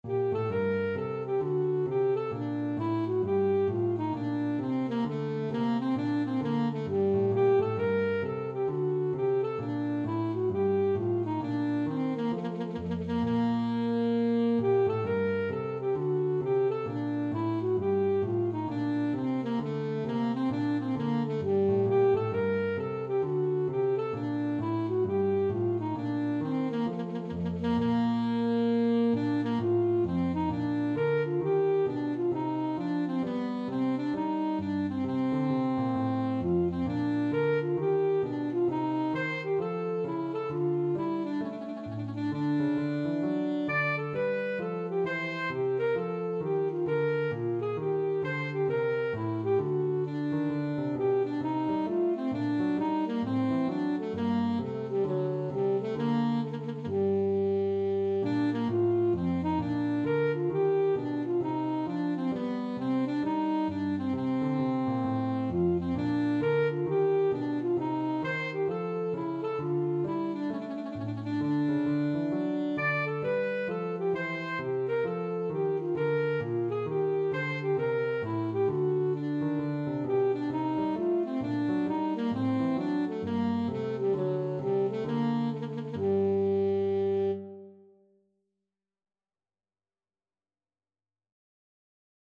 Classical Bach, Johann Sebastian Bourree from BWV 996 Alto Saxophone version
Alto Saxophone
4/4 (View more 4/4 Music)
G minor (Sounding Pitch) E minor (Alto Saxophone in Eb) (View more G minor Music for Saxophone )
Allegro = c. 132 (View more music marked Allegro)
Classical (View more Classical Saxophone Music)
bach_bourree996_ASAX.mp3